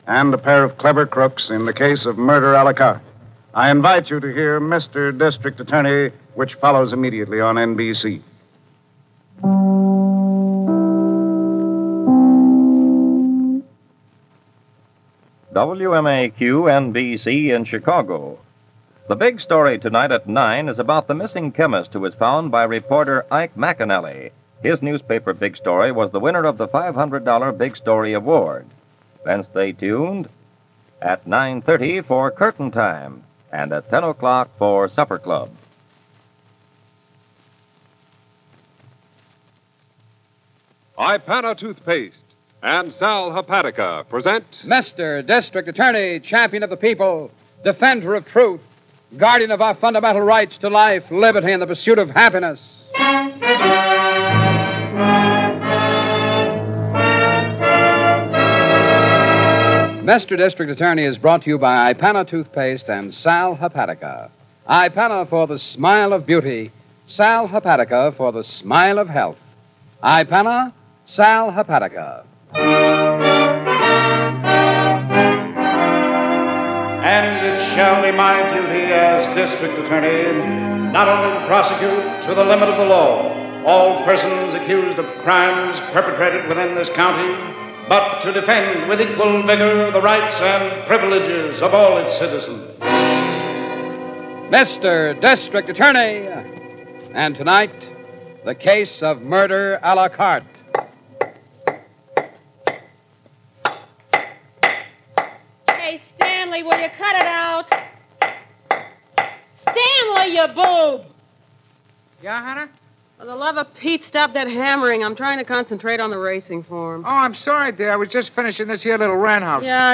District Attorney is a radio crime drama, produced by Samuel Bischoff, which aired on NBC and ABC from April 3, 1939, to June 13, 1952 (and in transcribed syndication through 1953).